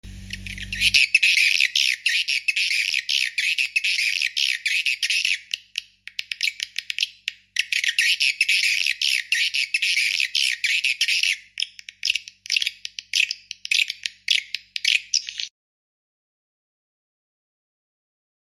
Swallow Sound Horn Tweeter SHD 270 sound effects free download